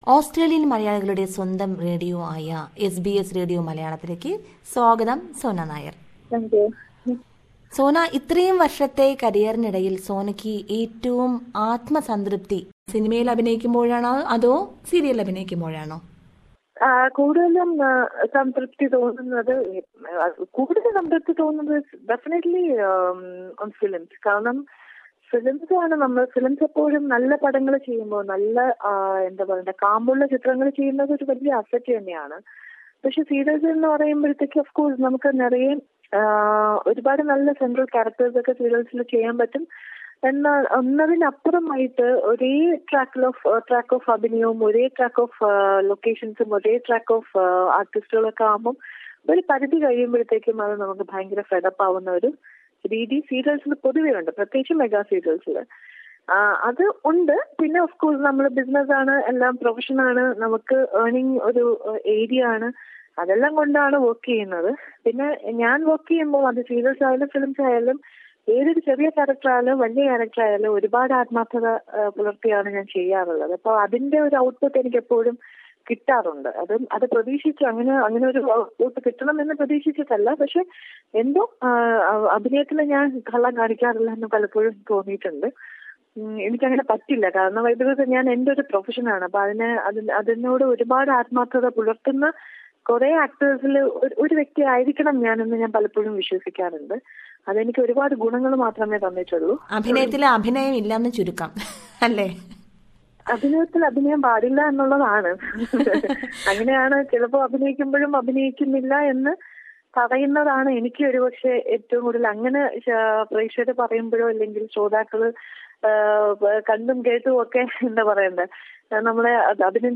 Here is yet another interview with a cine and serial actress.